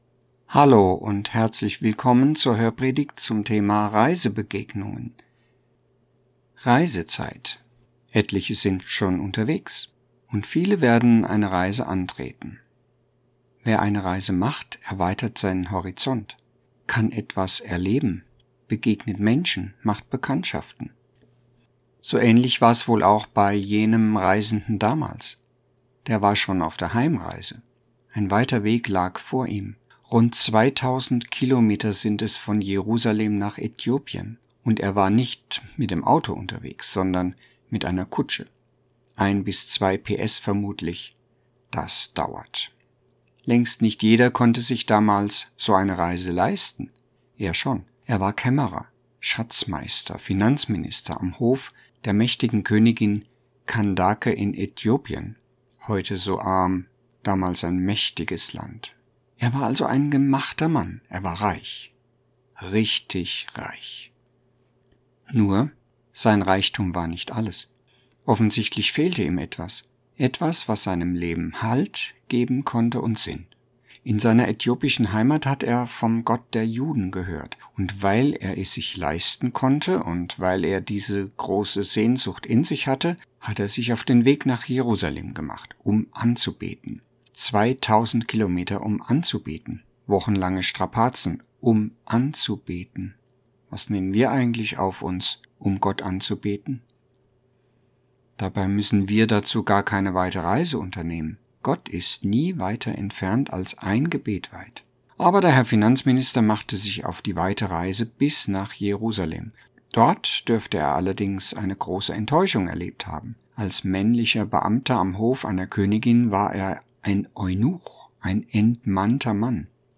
Hörpredigt Juli 2025 – Reisebegegnungen
Hörpredigt-ZV-Juli_2025.mp3